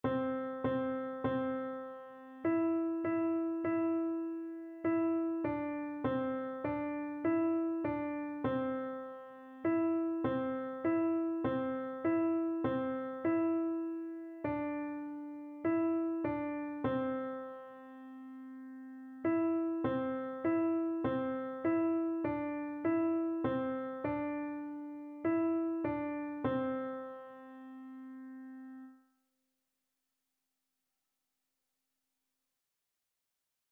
4/4 (View more 4/4 Music)
Beginners Level: Recommended for Beginners
Piano  (View more Beginners Piano Music)
Classical (View more Classical Piano Music)